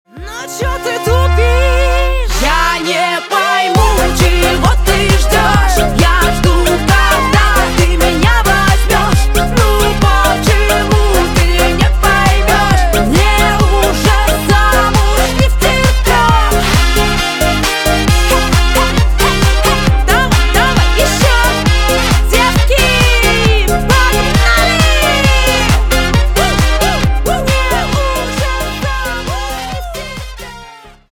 на русском веселые на парня